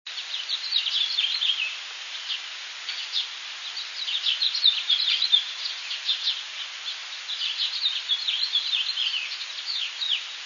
House Finch, Perth Amboy, 3/18/00, (41kb) third sequence ends with two descending "whoits" similar to cardinal
finch_house_299C.wav